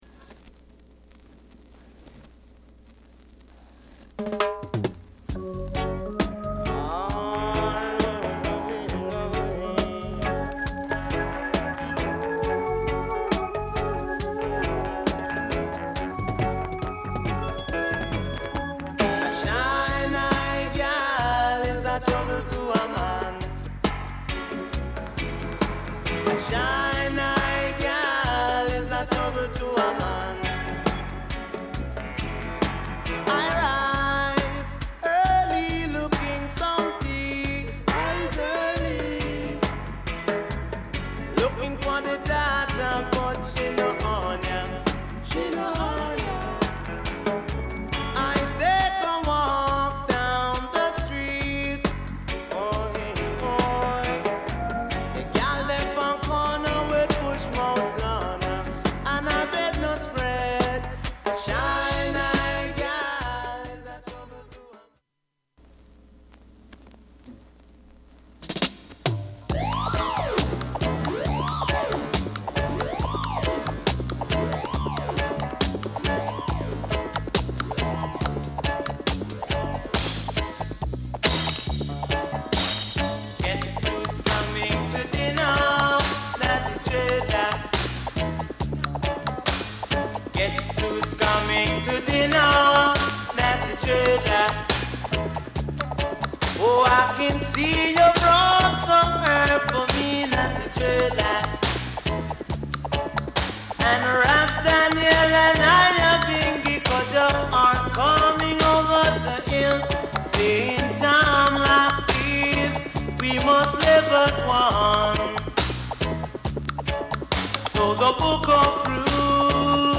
ROOTS REGGAE